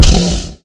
sounds / mob / horse / zombie / hit4.mp3